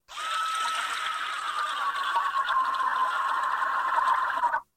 monster-sound